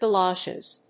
/ɡəˈlɒʃɪz(米国英語), gʌˈlɑ:ʃʌz(英国英語)/